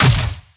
Plasma Rifle
LASRGun_Plasma Rifle Fire_04_SFRMS_SCIWPNS.wav